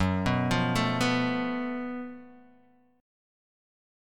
GbmM7bb5 chord